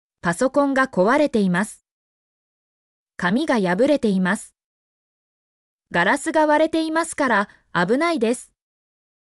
mp3-output-ttsfreedotcom-47_yvVKT98V.mp3